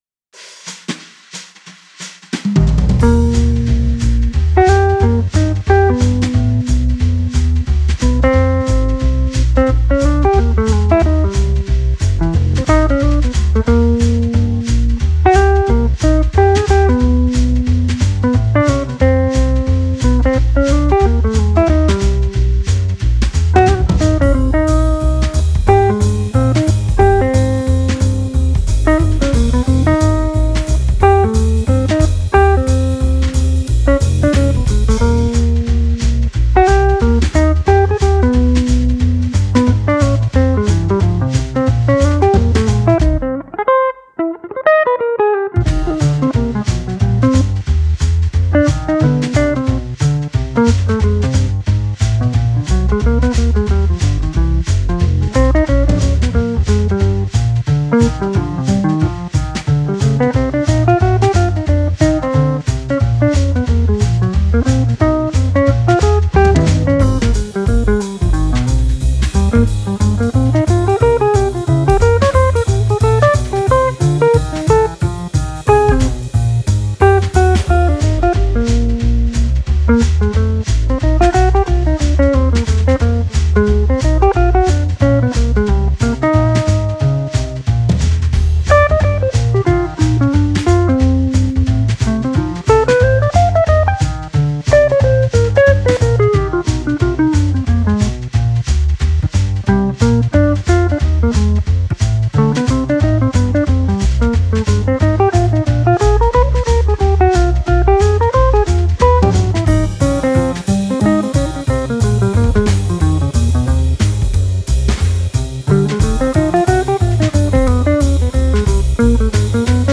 Good take and very nice souding jazz box.